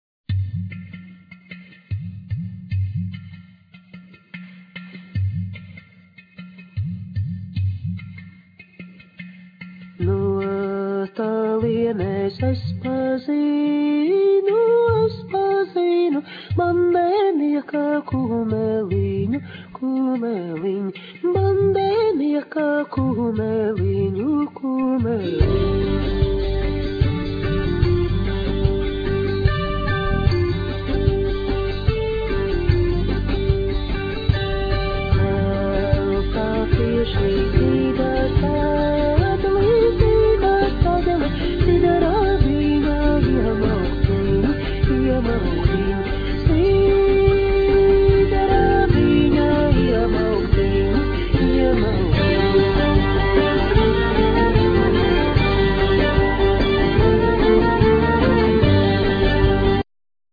Vocal,Violin
Vocal,Kokle,Bagpipe,Acordeon
Vocal,Bass,Giga
Vocal,Guitar
Percussions